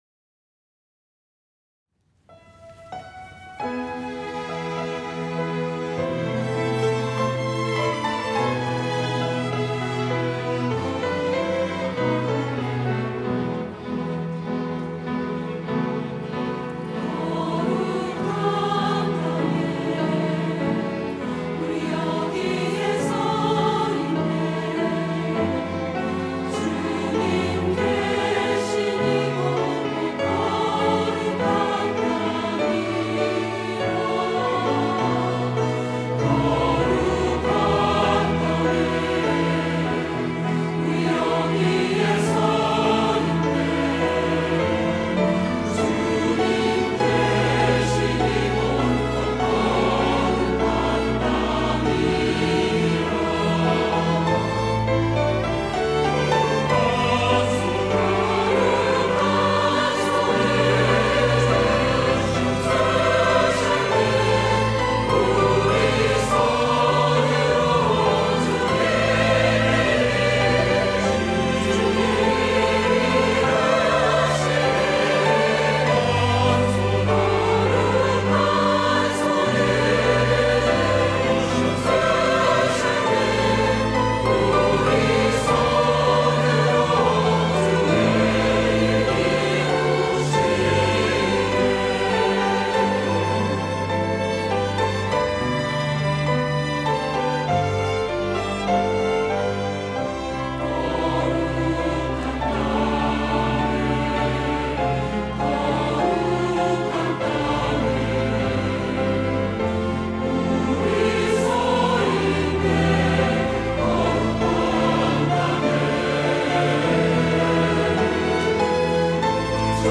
호산나(주일3부) - 거룩한 땅에
찬양대